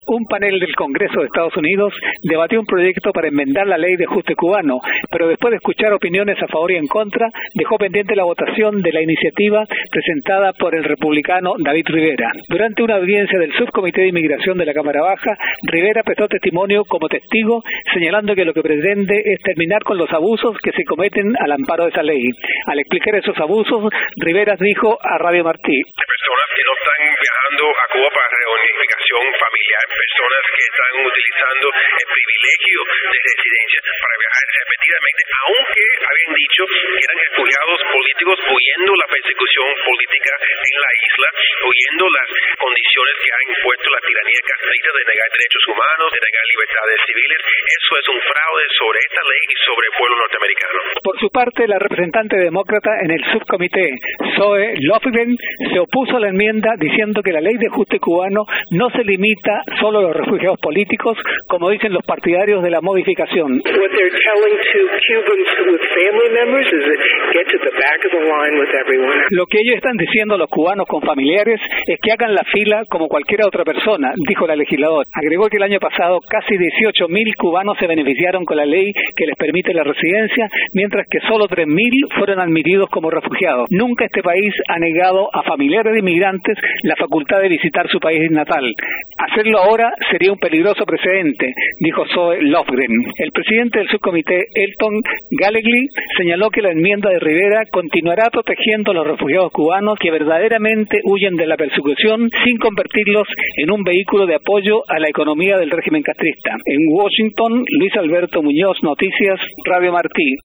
Declaraciones de legisladores